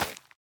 netherwart5.ogg